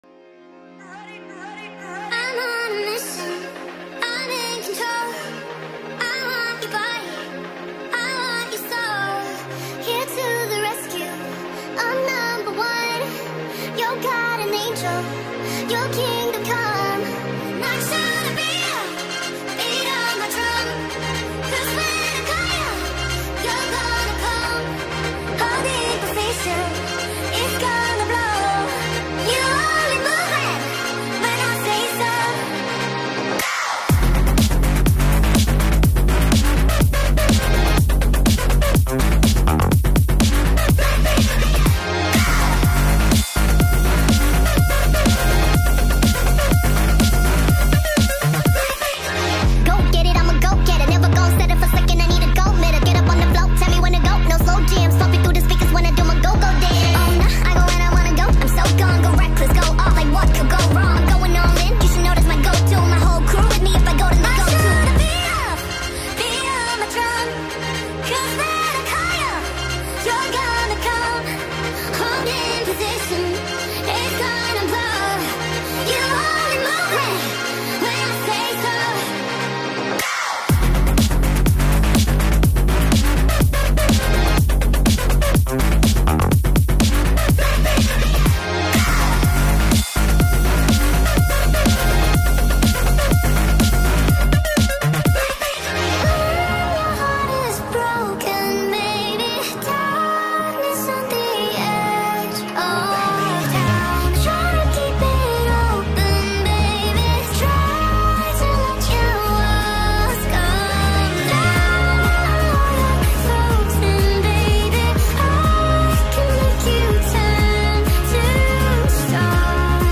speed up remix